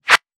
weapon_bullet_flyby_19.wav